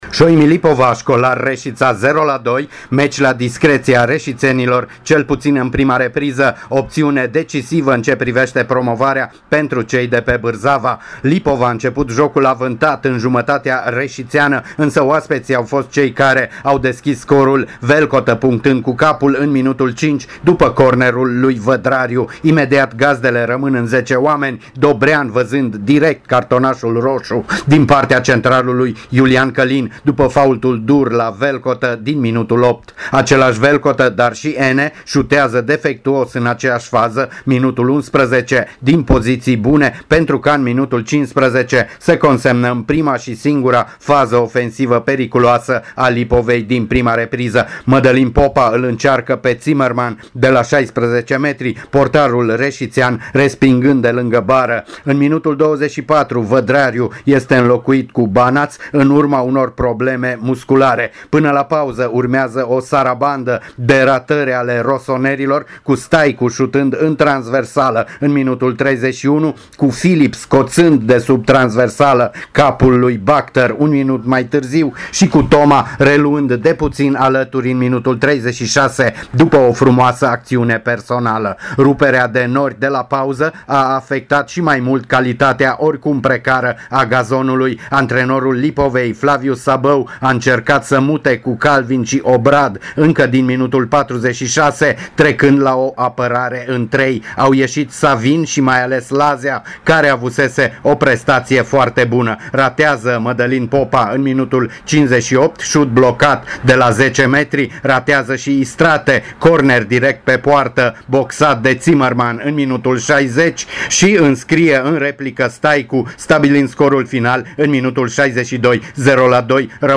cronica-Lipova-vs-Resita.mp3